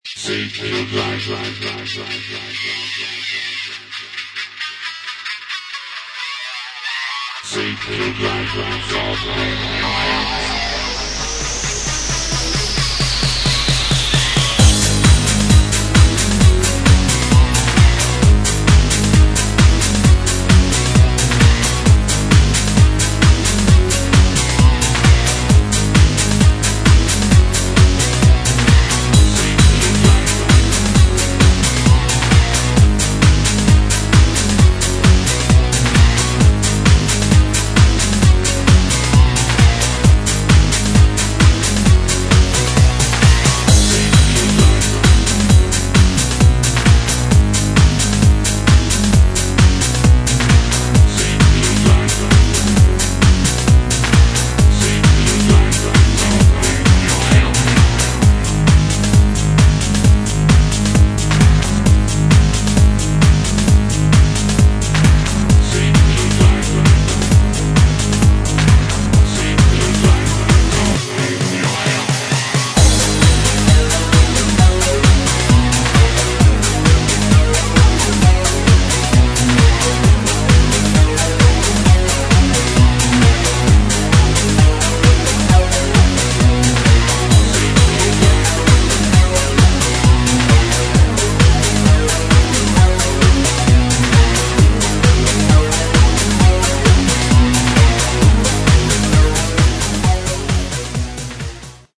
Style: Techno/House